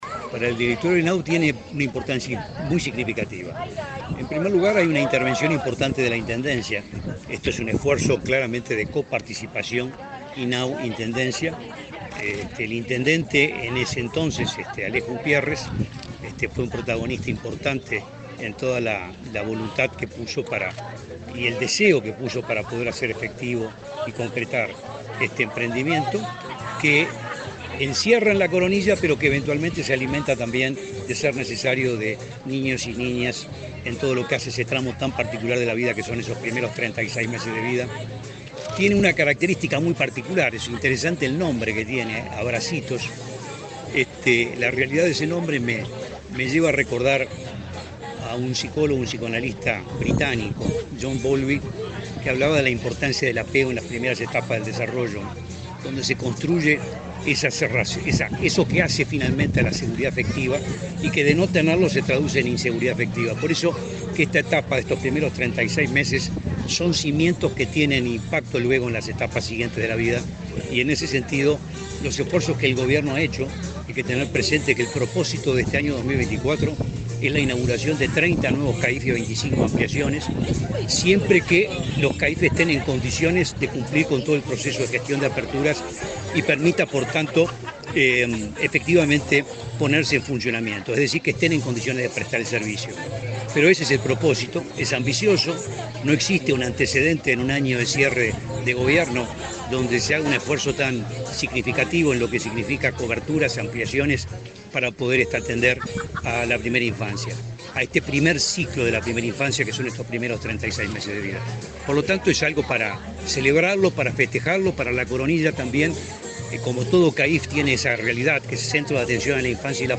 Declaraciones del presidente del INAU, Guillermo Fosatti
Declaraciones del presidente del INAU, Guillermo Fosatti 13/09/2024 Compartir Facebook X Copiar enlace WhatsApp LinkedIn El presidente del Instituto del Niño y Adolescente del Uruguay (INAU), Guillermo Fosatti, dialogó con la prensa, antes de participar en la inauguración del CAIF "Abracitos" en la localidad de La Coronilla, departamento de Rocha.